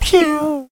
gray_dryfire_01.ogg